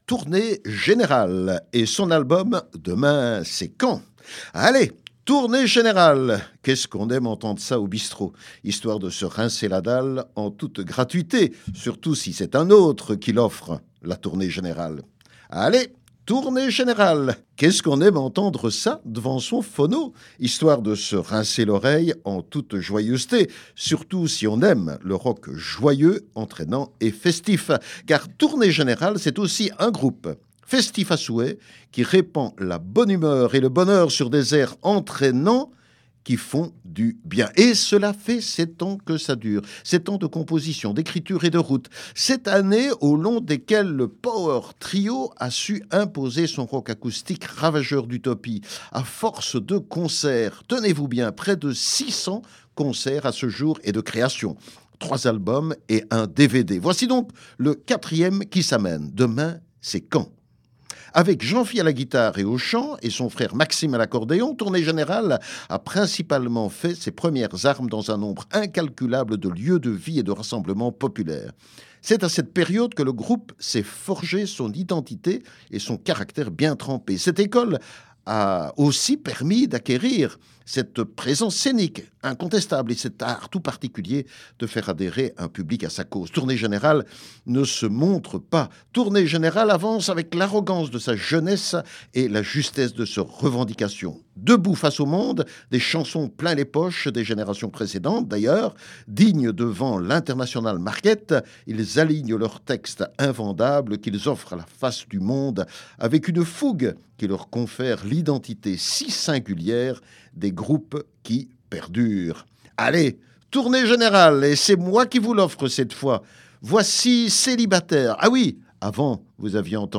rock acoustique ravageur d’utopie
punks dans l’âme et poètes en action.